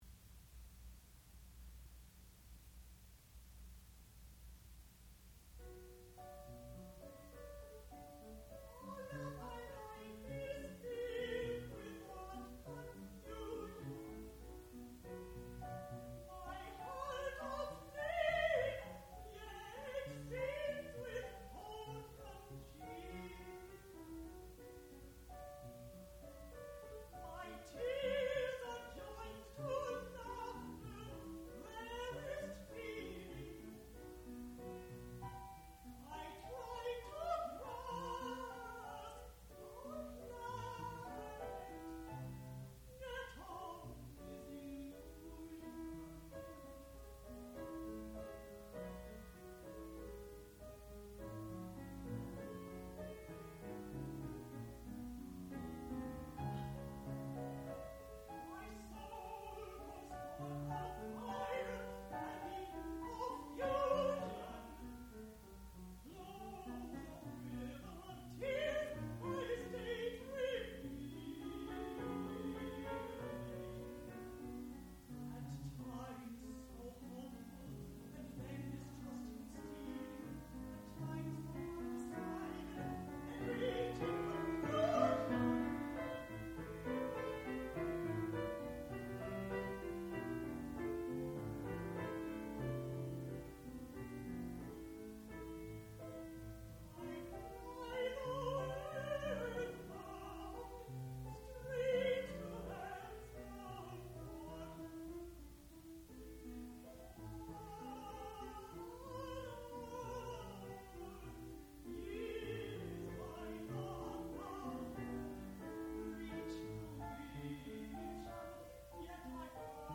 sound recording-musical
classical music
piano
Graduate Recital
mezzo-soprano